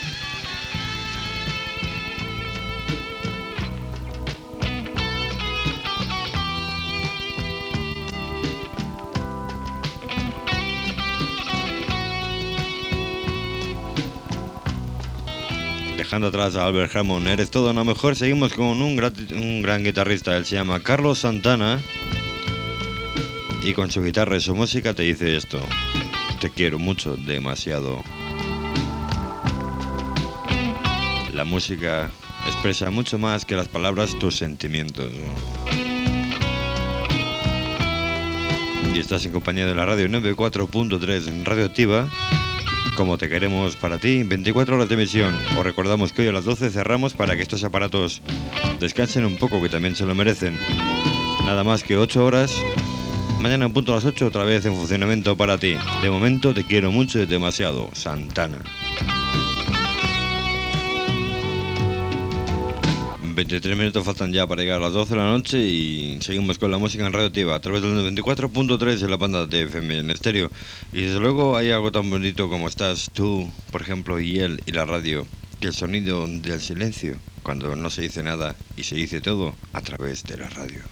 6443ac241c217051ab5c60082a423e03d2ccf16c.mp3 Títol Radio Activa Emissora Radio Activa Titularitat Tercer sector Tercer sector Comercial Descripció Tema musical, identificació i avís del tancament d'emissió.